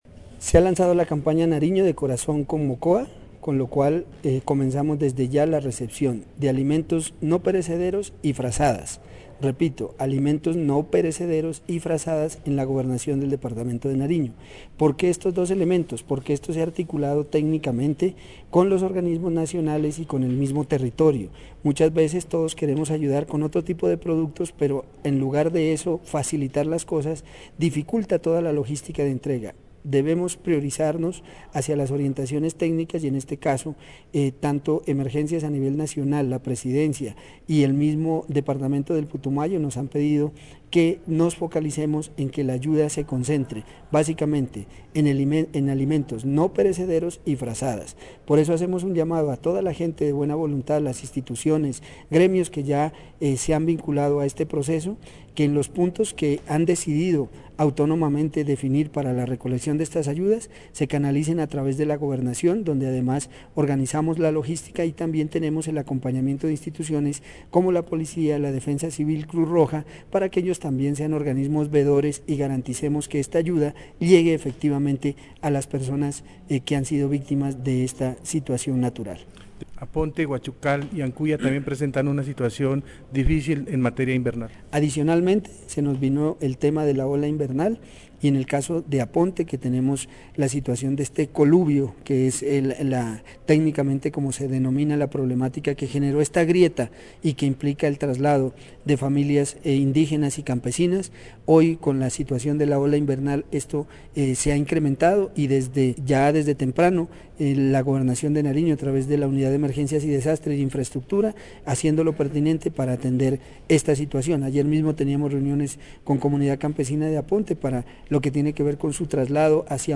Entrevista a Mario Benavides, secretario de Hacienda de Nariño.